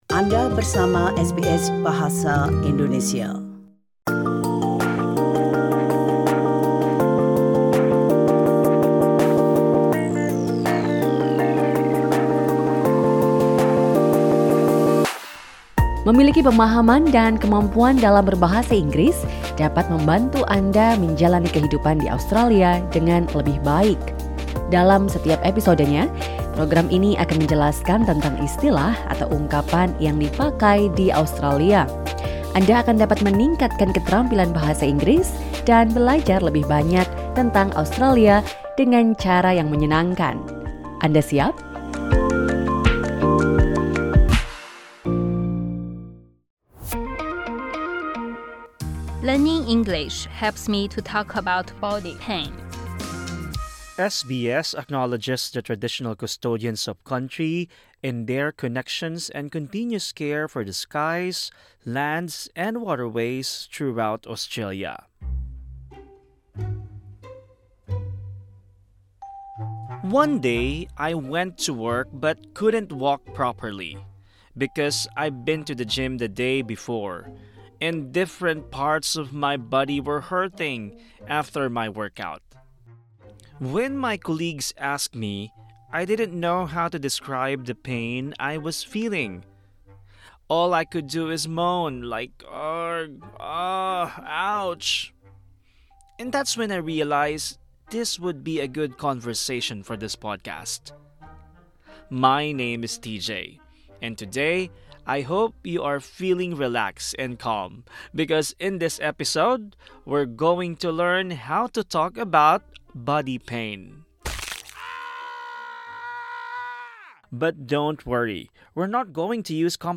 Selain itu, dengarkan beberapa tips dari fisioterapis jika Anda merasakan nyeri.